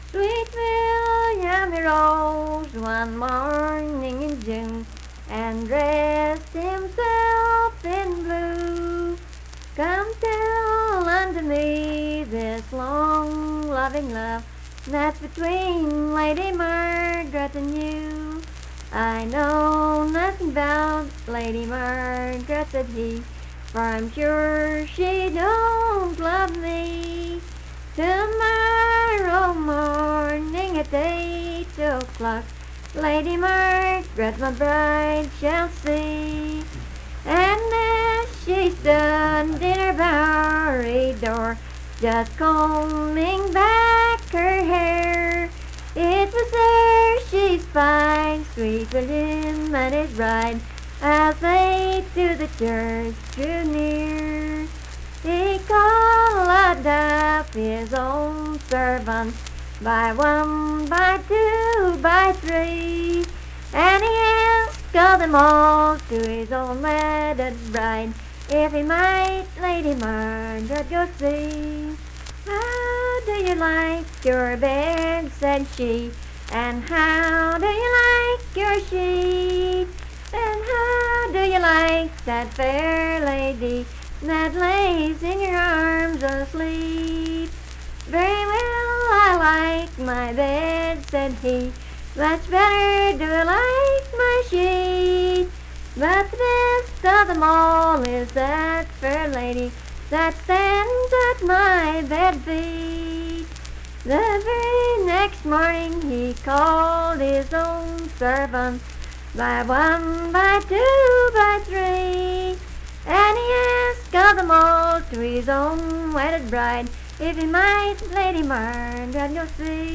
Unaccompanied vocal music
Performed in Strange Creek, Braxton, WV.
Voice (sung)